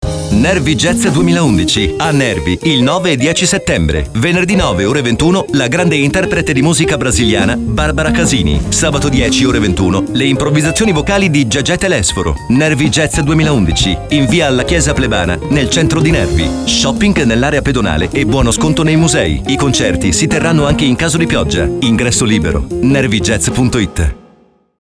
Spot Radio (mp3)
NJ11-Babboleo_Spot.mp3